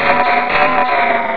cries
cradily.aif